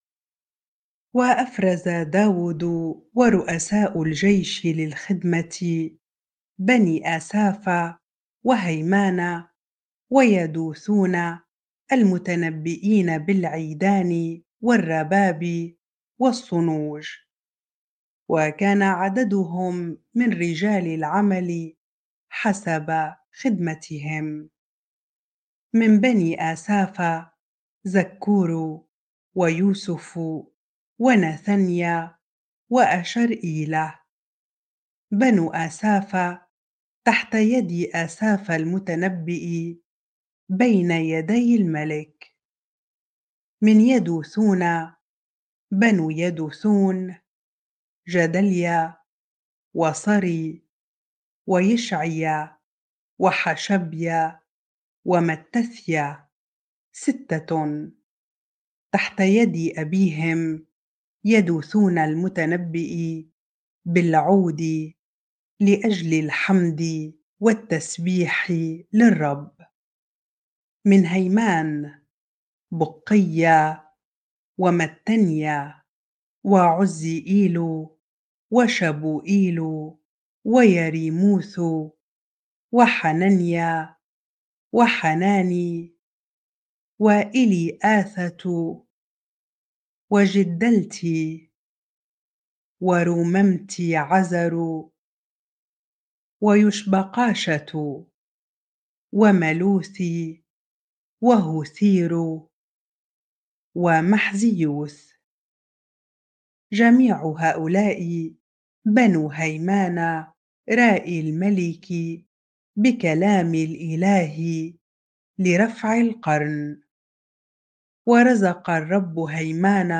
bible-reading-1 Chronicles 25 ar